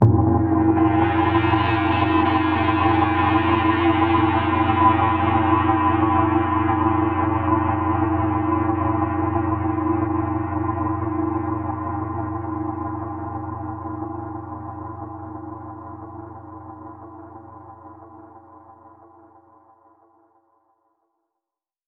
Index of /musicradar/impact-samples/Processed Hits
Processed Hits 01.wav